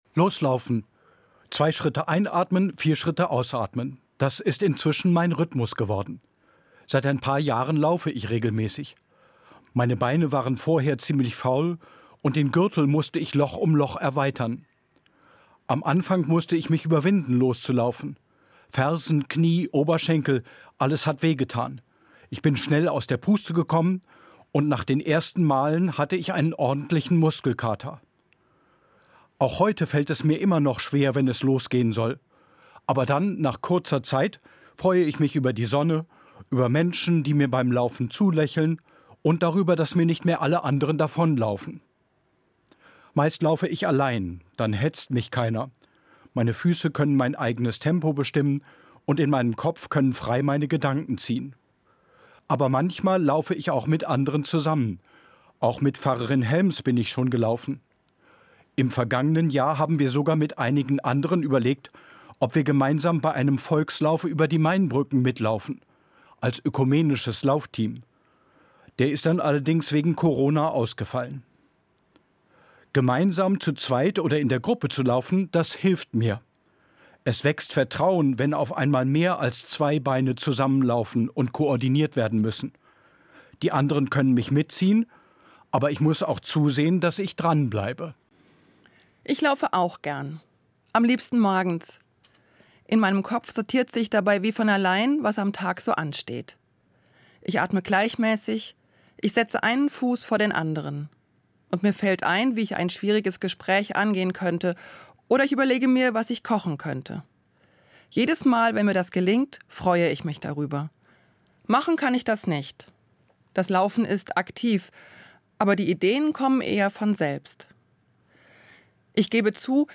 Ökumenische Morgenfeier